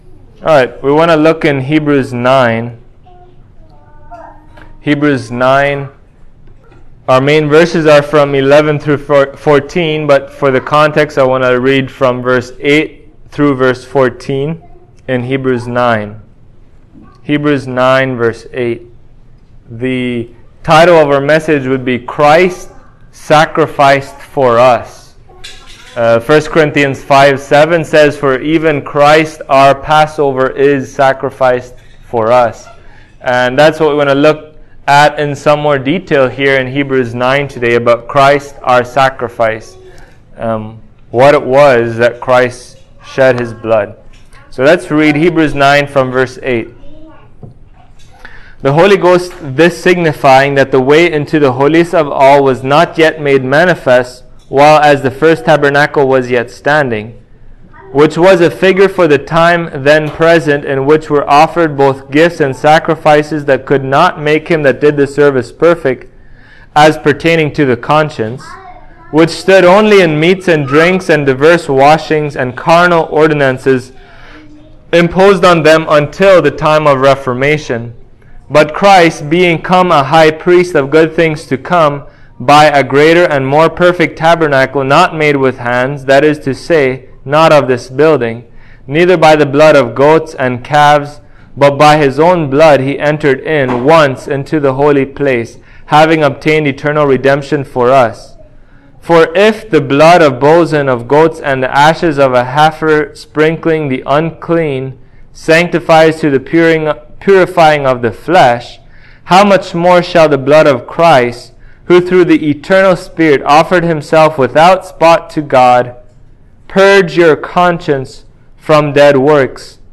Hebrews 9:8-14 Service Type: Sunday Morning Jesus is the promised Messiah of Israel!